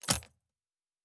pgs/Assets/Audio/Fantasy Interface Sounds/Locker 5.wav at master
Locker 5.wav